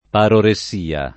paroressia [ parore SS& a ] s. f. (med.)